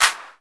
Звуки хлопков
Тыцк